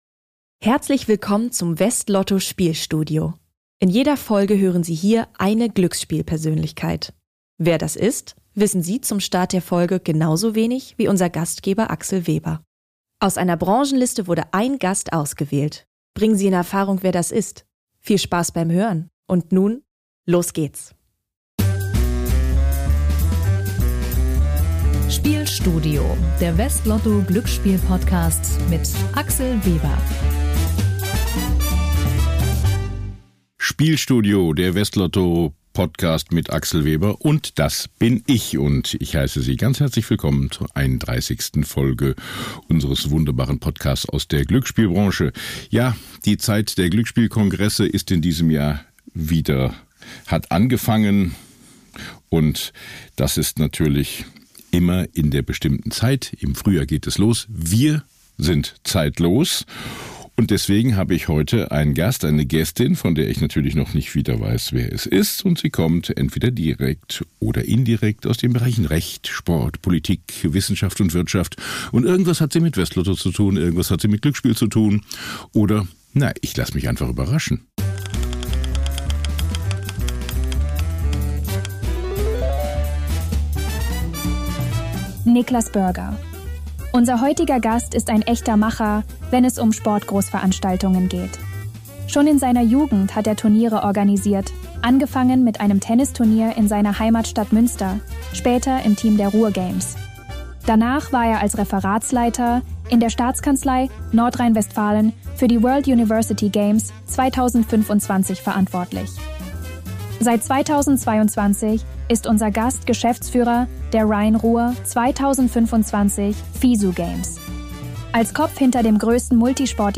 Hinweis: Diese Folge enthält einen Zuspieler, der von einer KI-Stimme gesprochen wird.